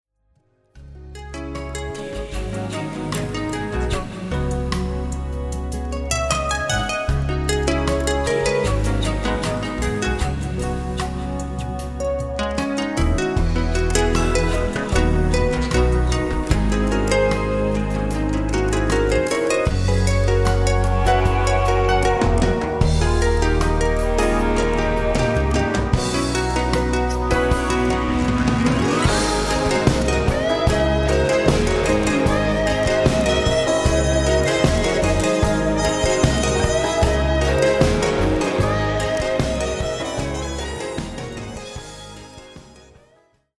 Recorded & mixed at Powerplay Studios, Maur – Switzerland